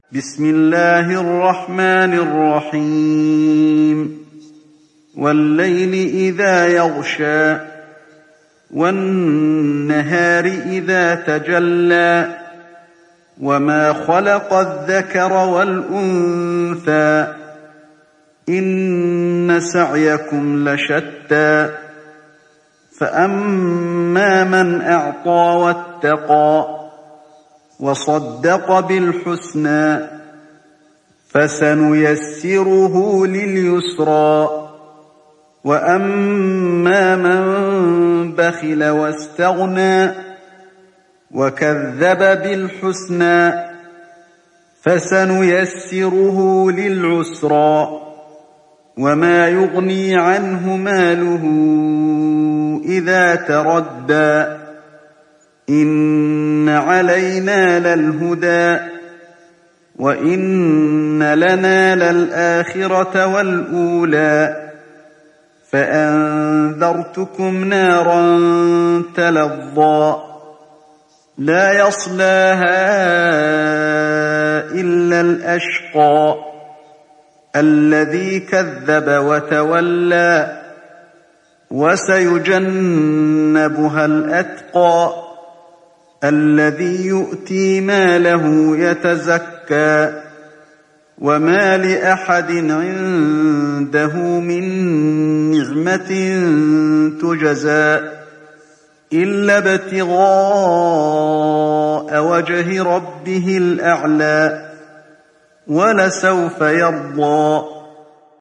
Riwayat Hafs an Asim